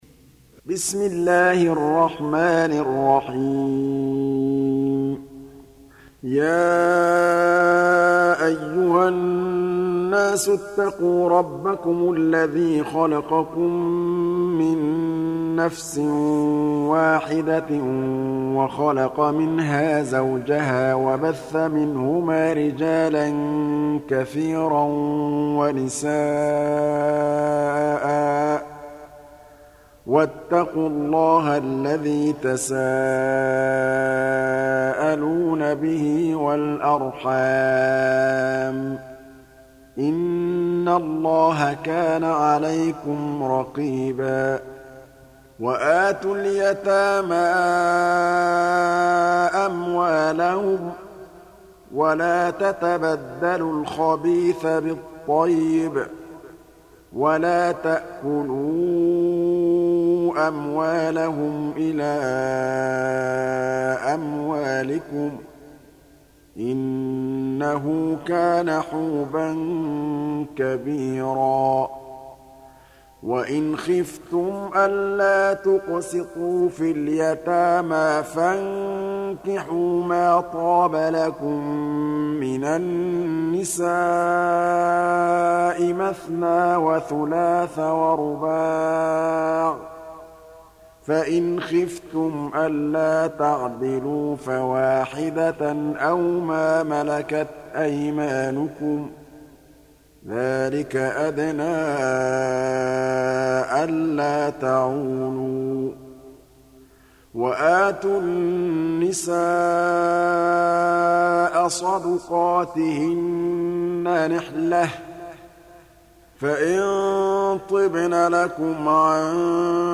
Surah Sequence تتابع السورة Download Surah حمّل السورة Reciting Murattalah Audio for 4. Surah An-Nis�' سورة النساء N.B *Surah Includes Al-Basmalah Reciters Sequents تتابع التلاوات Reciters Repeats تكرار التلاوات